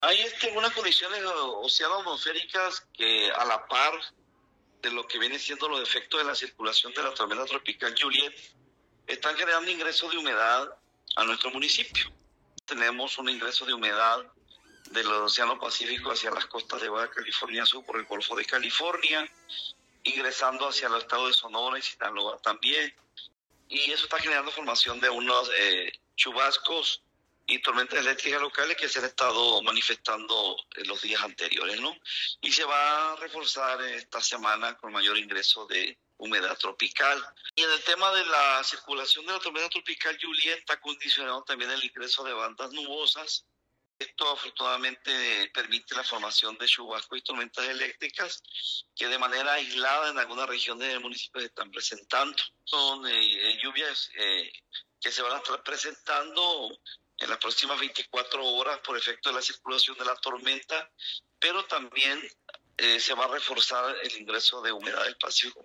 Francisco Cota Márquez – director municipal de Protección Civil del XV Ayuntamiento de Los Cabos
Inserto-director-Proteccion-Civil-Los-Cabos-Lluvias-.mp3